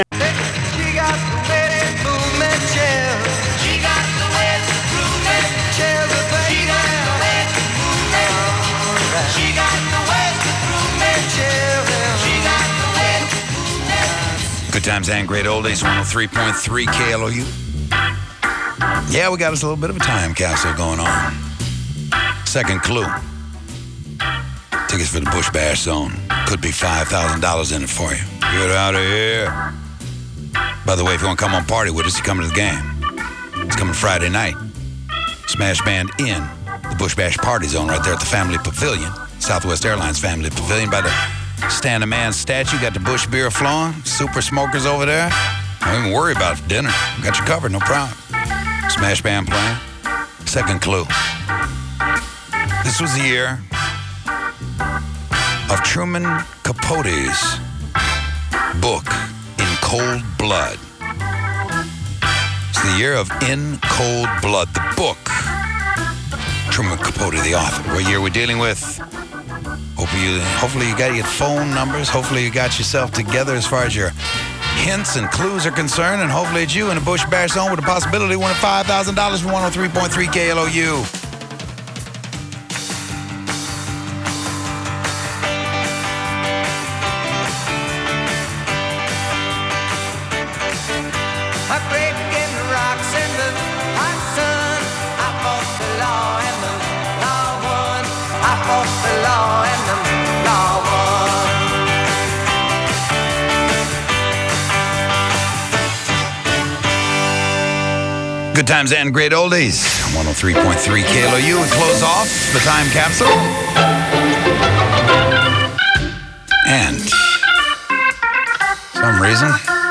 KLOU Smash Aircheck · St. Louis Media History Archive